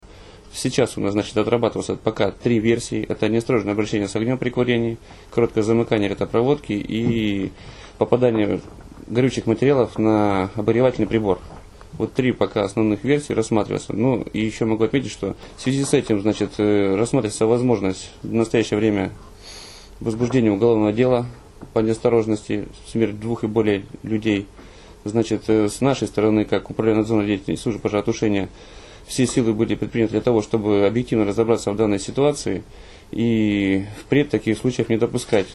«Сейчас отрабатывается три версии: неосторожное обращение с огнем во время курения, короткое замыкание электропроводки и попадание горючих материалов на обогревательный прибор», - рассказал главный государственный инспектор области по пожарному надзору Андрей Шашин.